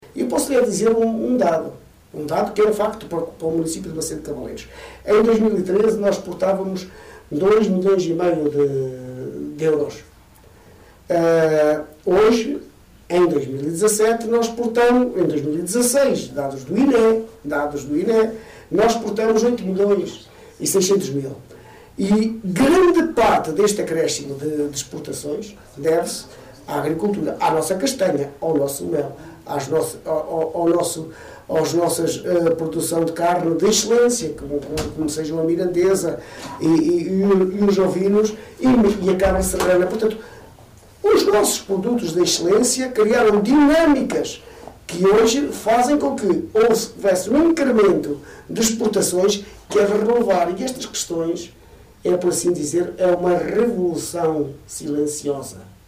A mostra que passou a ser feira tem este ano como novidade maior o investimento na formação dos agricultores, com workshops, demonstrações e um colóquio, avançou em entrevista à Onda Livre Carlos Barroso, vice-presidente da autarquia.